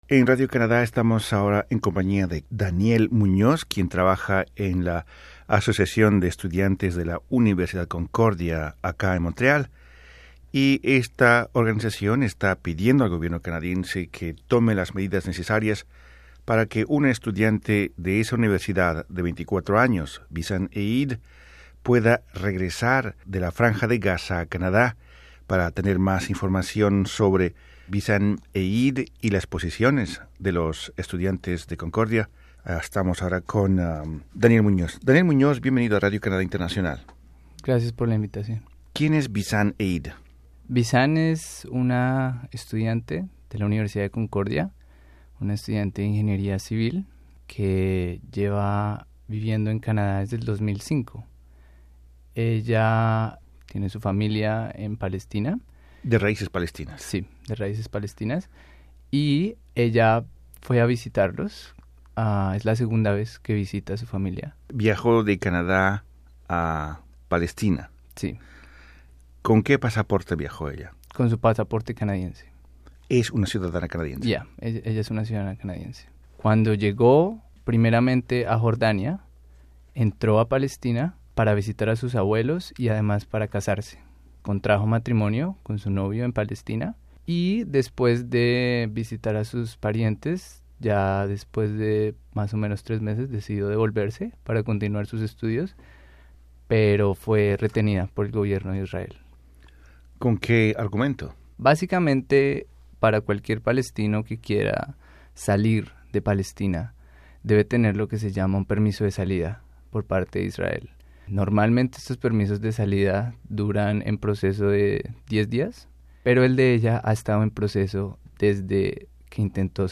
Entrevistado por Radio Canadá Internacional,